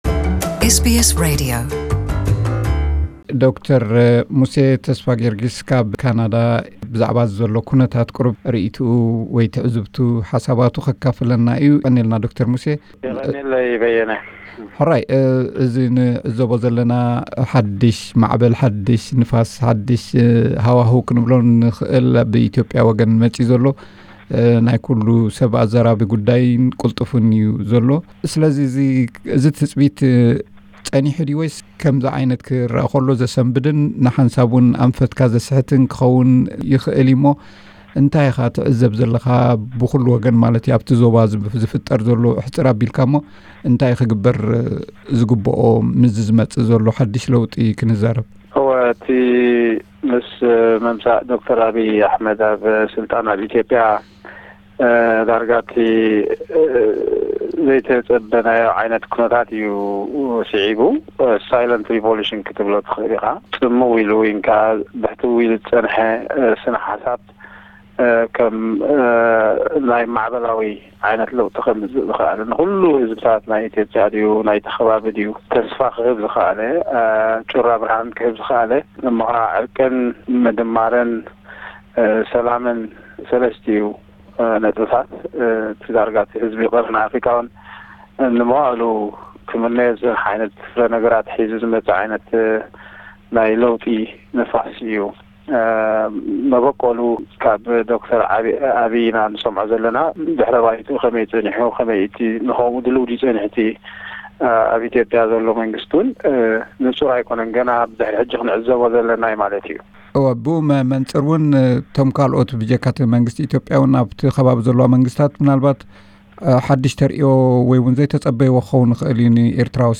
ምስ ረዲዮ ኤስ.ቢ.ኤስ ዝገበሮ ዝርርብ